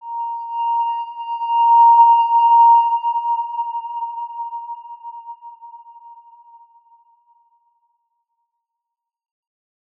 X_Windwistle-A#4-pp.wav